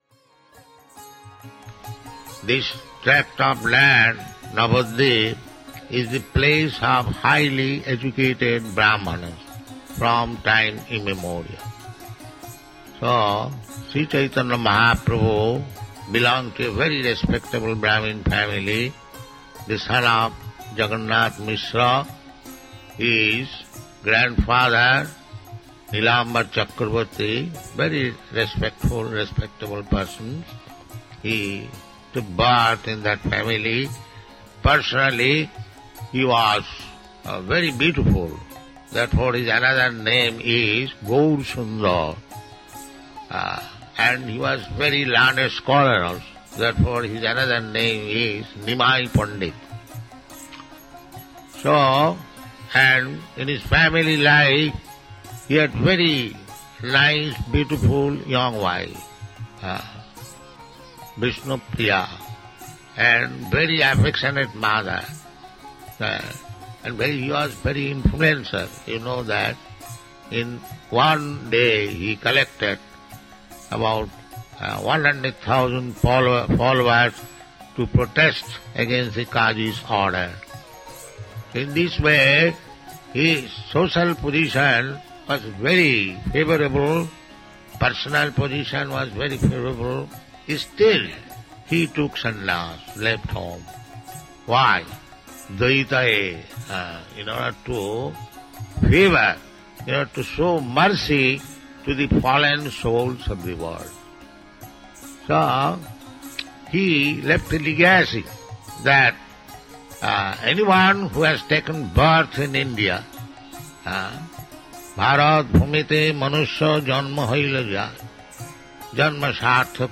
(760205 - Lecture Initiation Sannyasa Excerpt - Mayapur